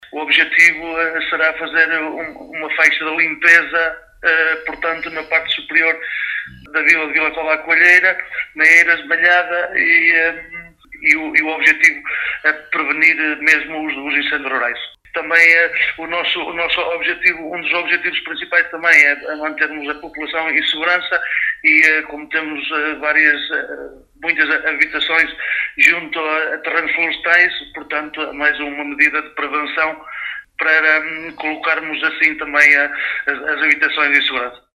Fernando Guedes, Presidente da Junta de Freguesia de Vila Cova à Coelheira, disse que o objetivo principal é fazer uma faixa de limpeza, para a prevenção contra os incêndios rurais e assim, salvaguardar pessoas e bens.